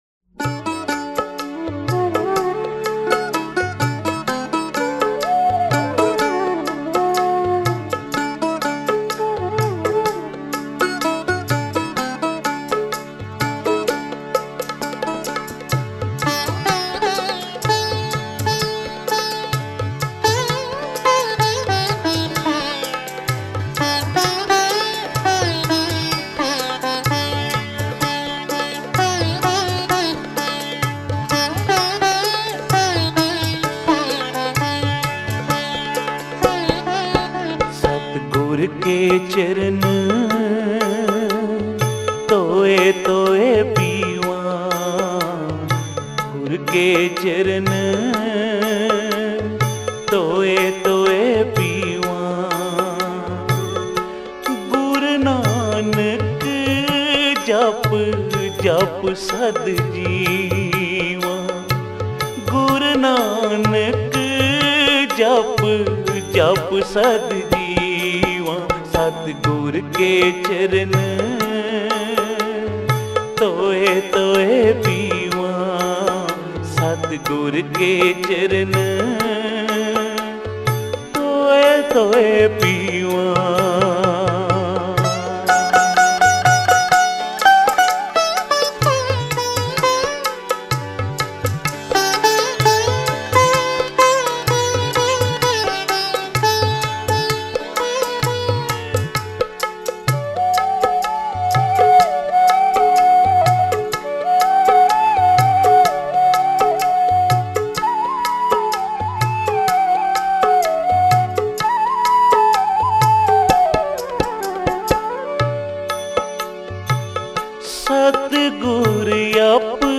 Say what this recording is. Genre: Gurmat Vichar